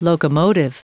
Prononciation du mot locomotive en anglais (fichier audio)
Prononciation du mot : locomotive